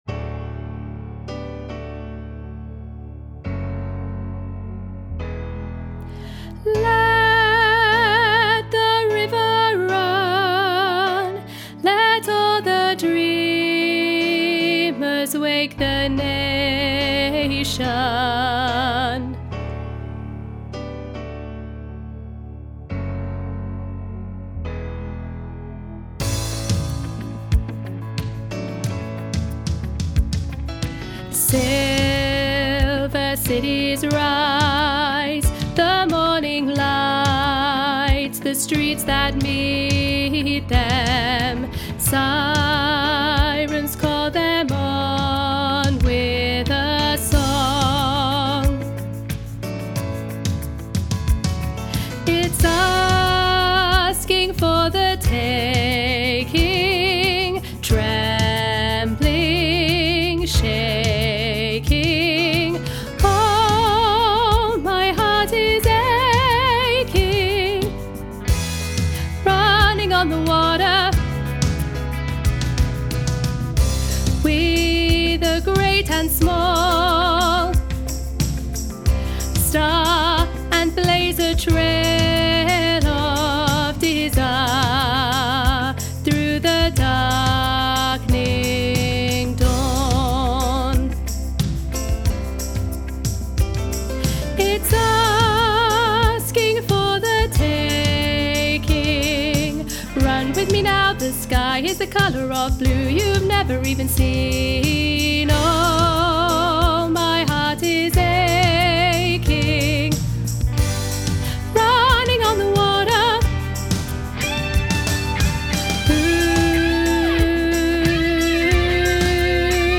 let-the-river-run-alto.mp3